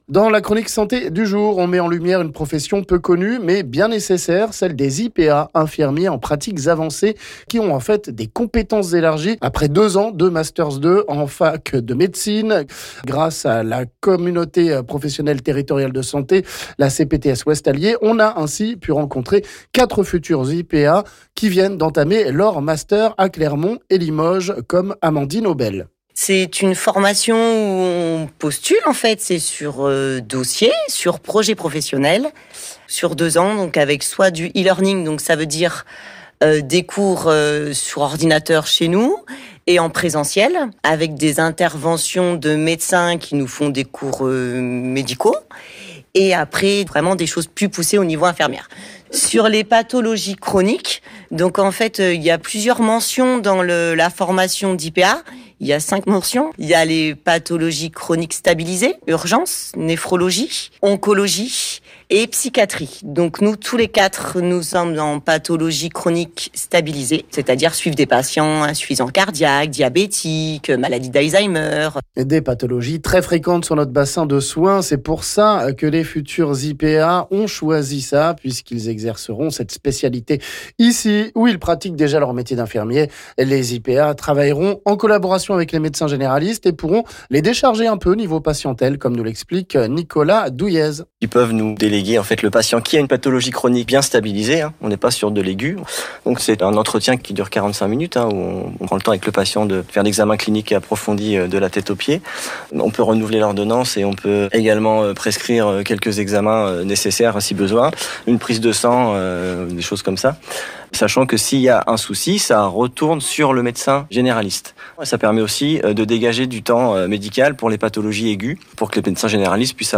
Grâce à la CPTS Ouest Allier, on a interviewé 4 futurs IPA